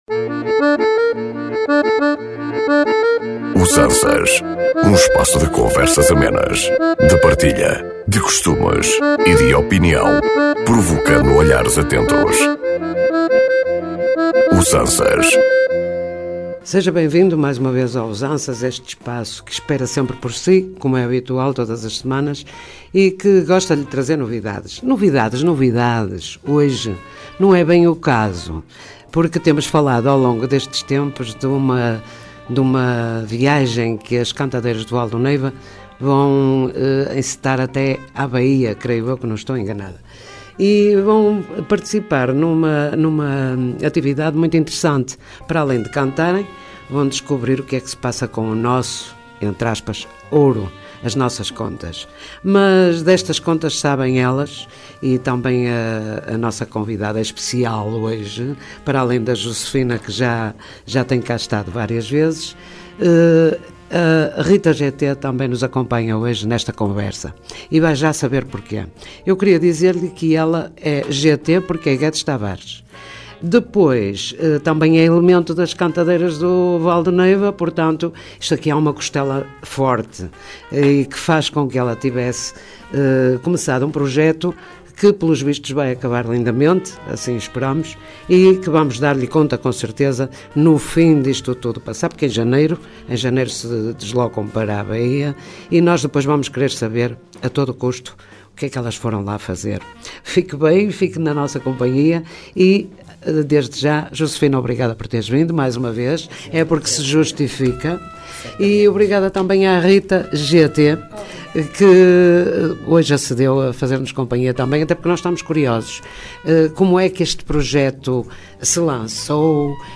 Um espaço de conversas amenas, de partilha, de costumes e de opinião, provocando olhares atentos.